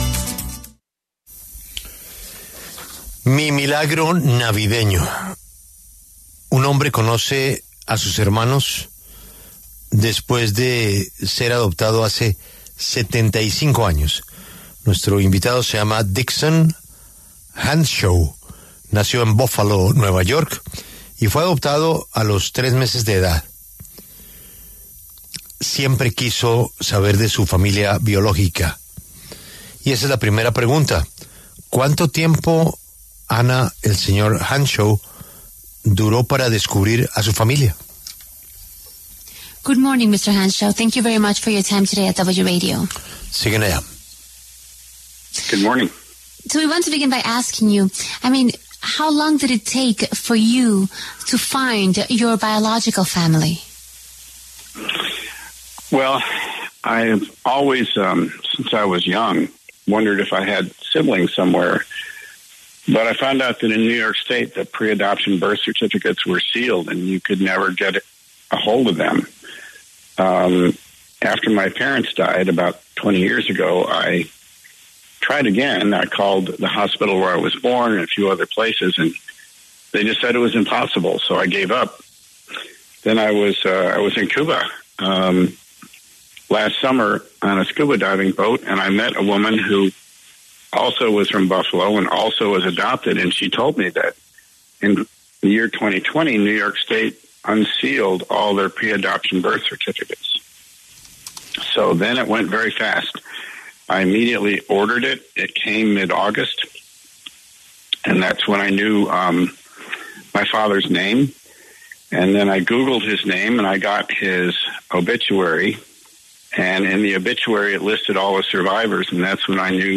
pasó por los micrófonos de La W